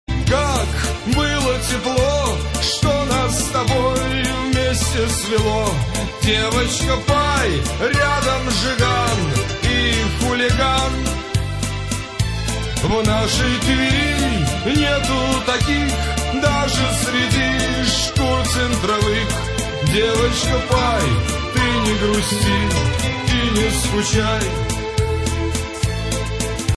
Шансон, Авторская и Военная песня